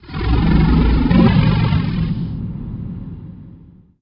PixelPerfectionCE/assets/minecraft/sounds/mob/enderdragon/growl1.ogg at mc116
growl1.ogg